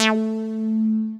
synTTE55009shortsyn-A.wav